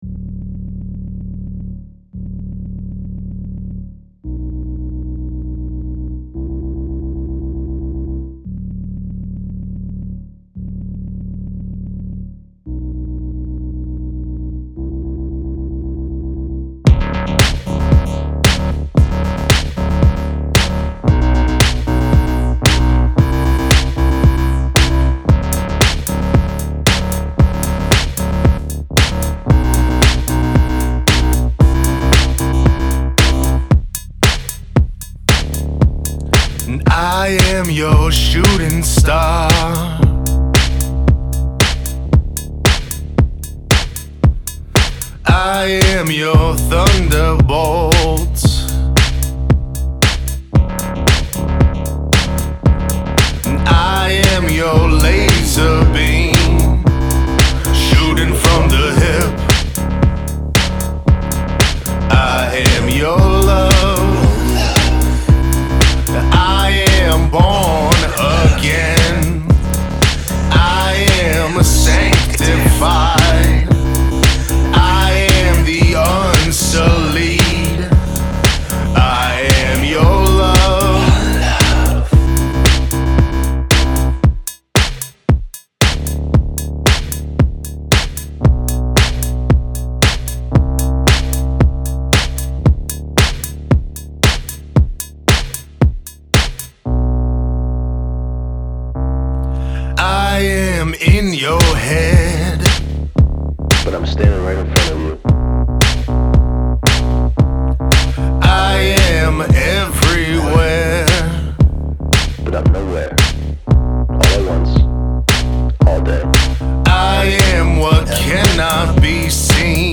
industrial rock and club dance music
enigmatic yet head-bobbing vibe